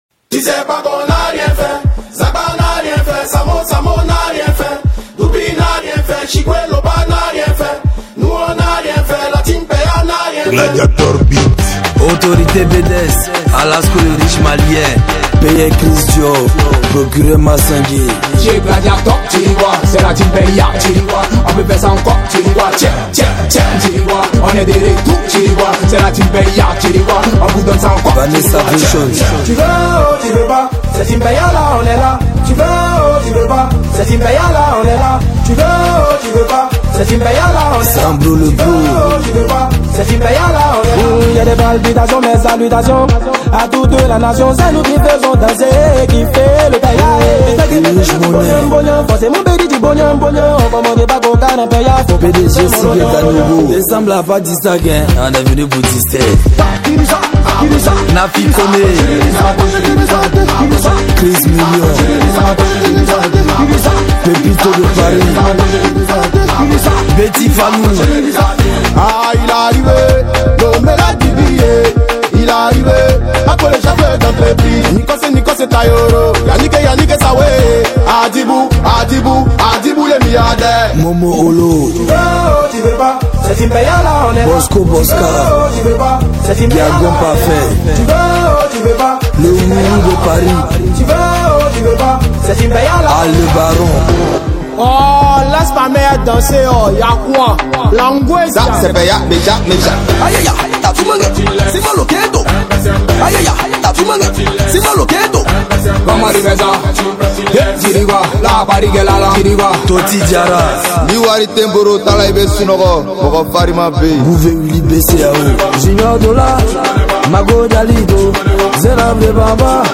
| Coupé décalé